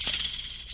Percs
DrShake4.wav